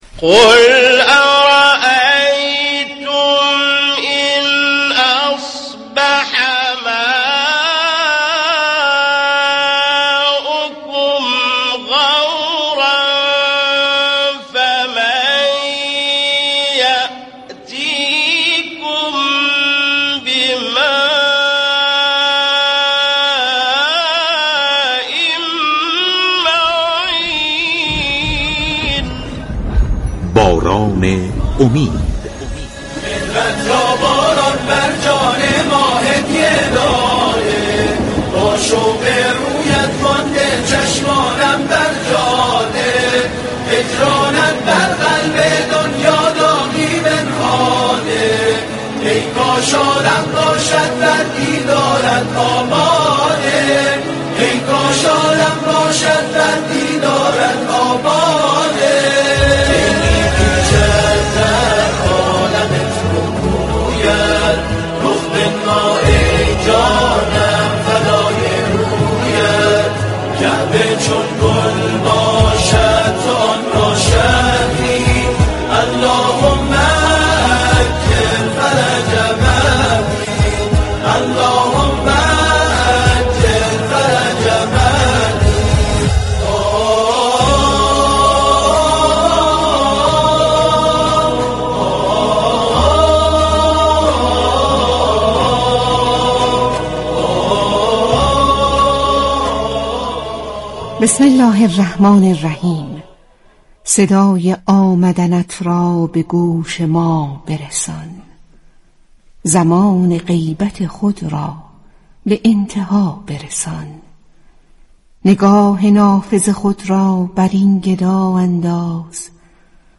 در برنامه زنده باران امید به تاریخ 14 اردیبهشت 1403 موضوع سنتهای الهی در قرآن كریم و ارتباط آن با موضوع مهدویت مورد بحث و بررسی قرار گرفت.